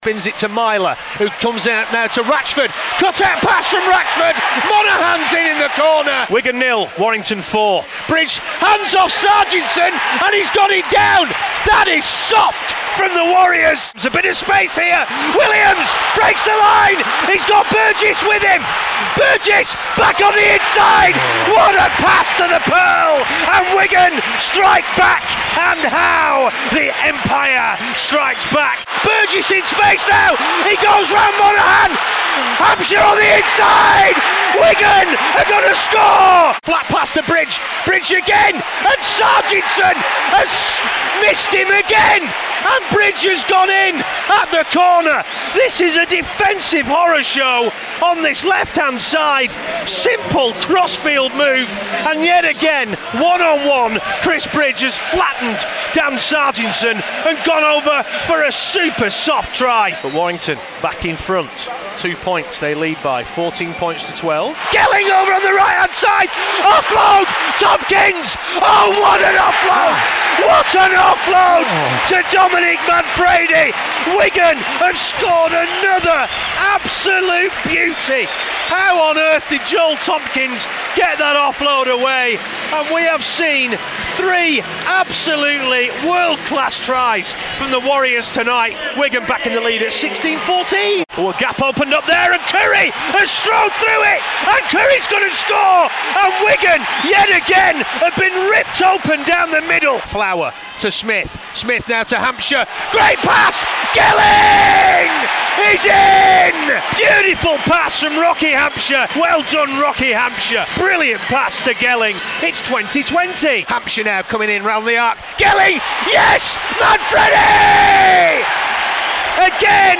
Listen again to Wigan's 'irresistable' win over the Wolves at the DW on the night Ben Flower returned from a six month ban and Sam Tomkins announced his return home. Highlights of the commentary